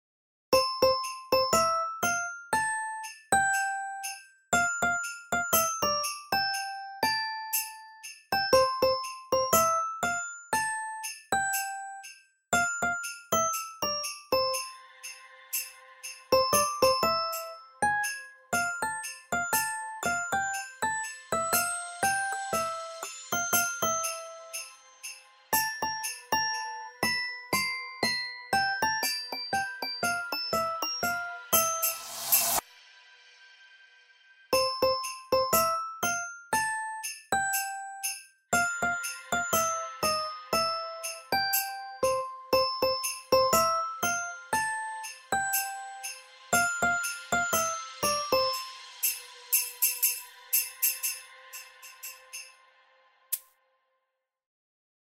ショートポップ明るい穏やか